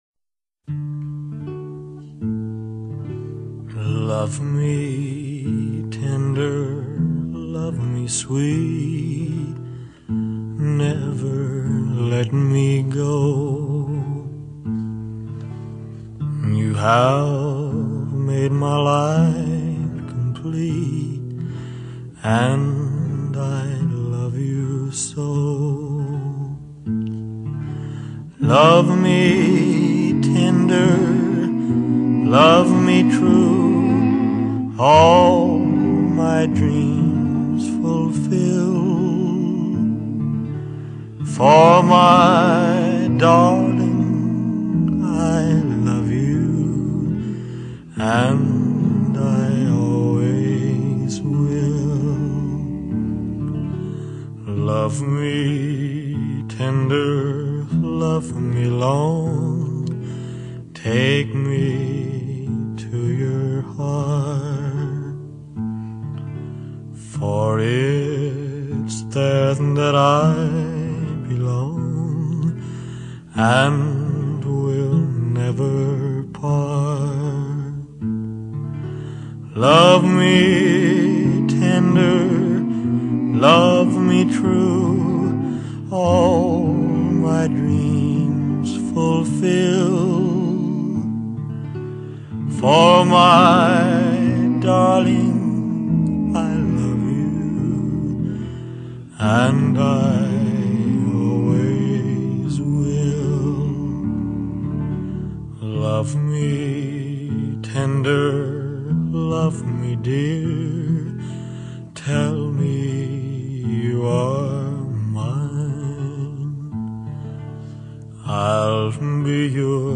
经典老歌，浪漫怀旧！